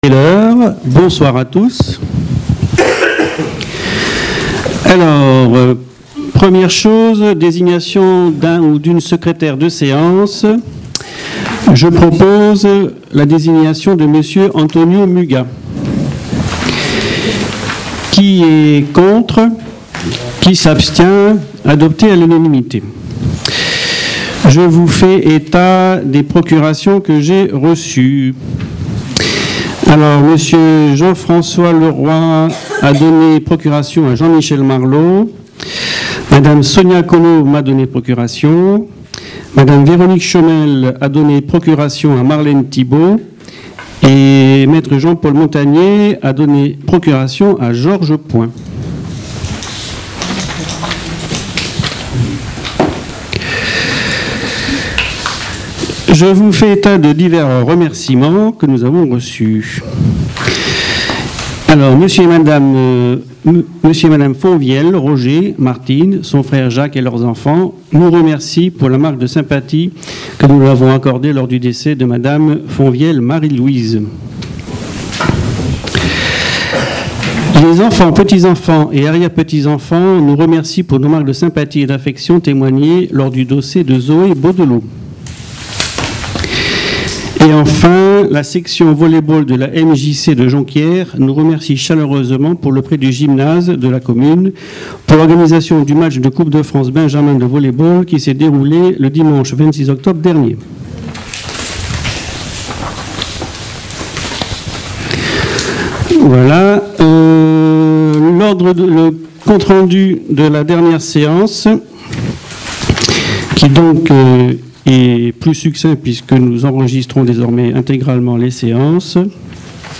Cliquez ici pour écouter l'enregistrement intégral du Conseil municipal du 27 novembre 2014.